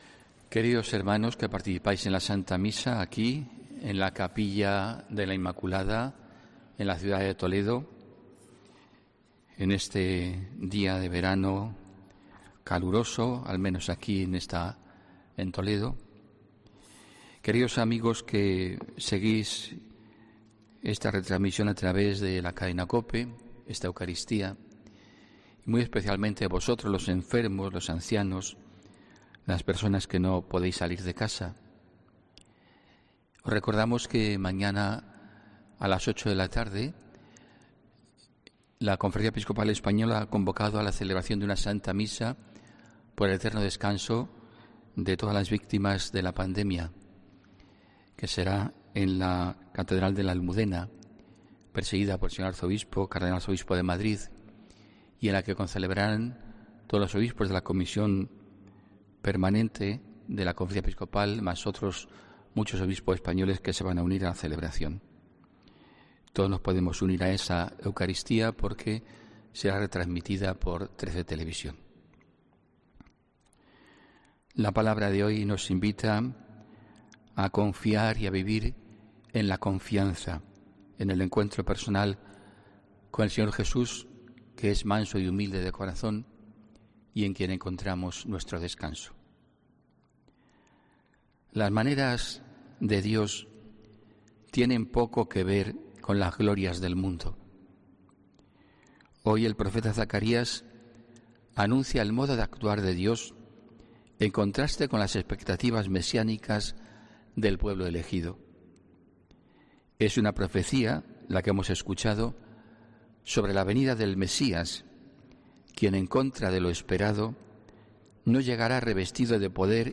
HOMILÍA 5 JULIO 2020